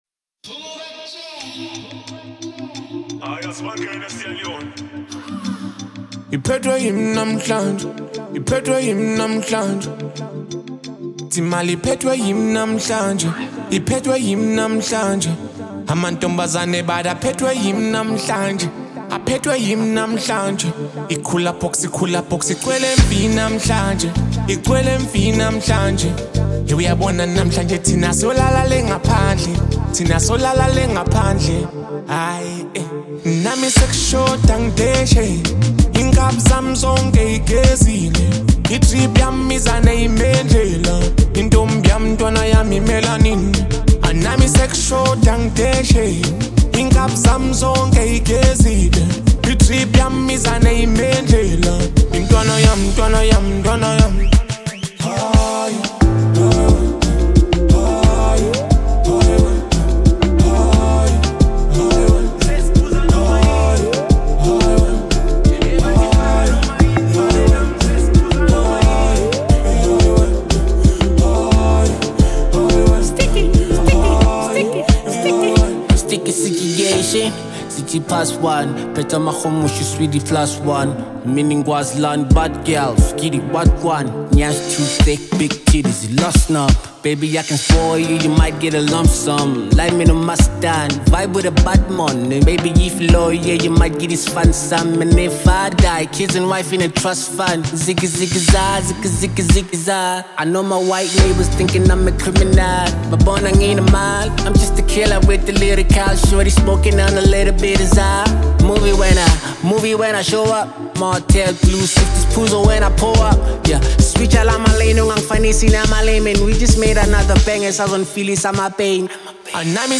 scorching verses